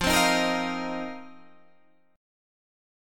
Listen to F7sus4 strummed